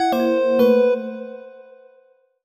jingle_chime_23_negative.wav